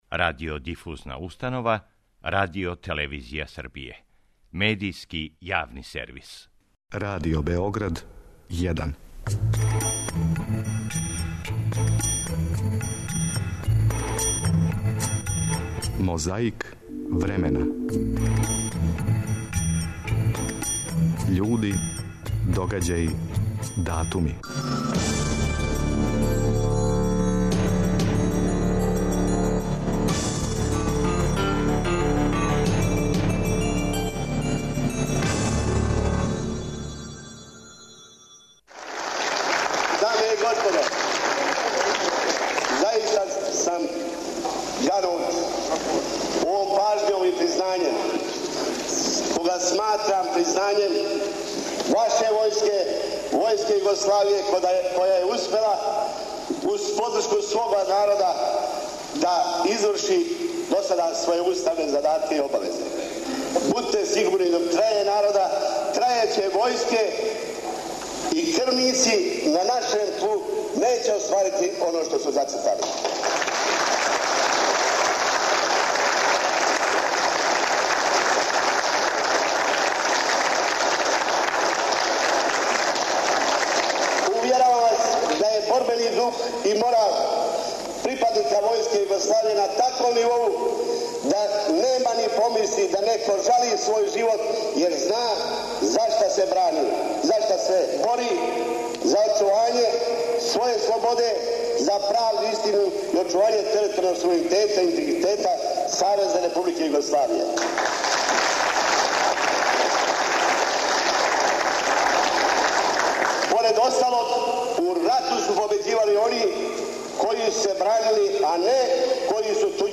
Присећамо се како је говорио генерал пуковник Драгољуб Ојданић 15. априла 1999. године у београдском Дому војске.
У нашој коцкици космонаут лети, прича, пева.